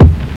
Closed Hats
Hat (65).wav